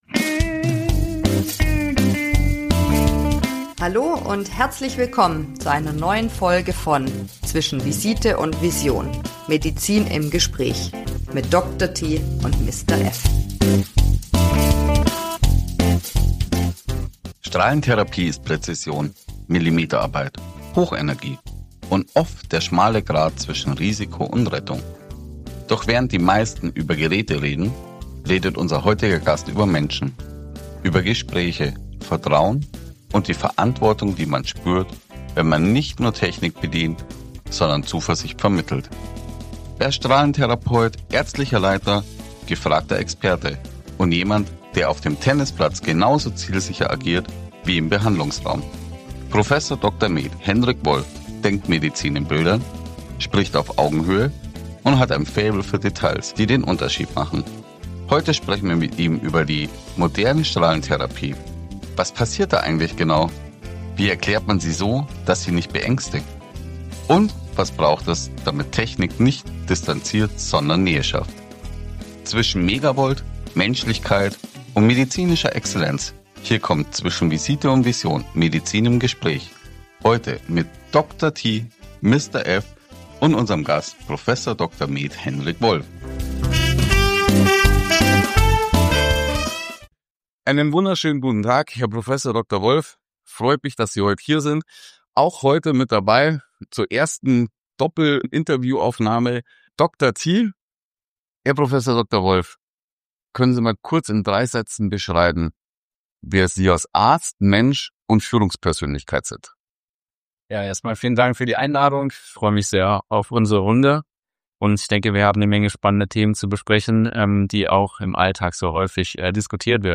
Wir entschuldigen uns für kleinere Tonstörungen durch eine im Haus befindliche Zahnarztpraxis.